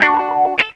GTR 37 AM.wav